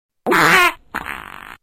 Whatthe Meme Sound Effect sound effects free download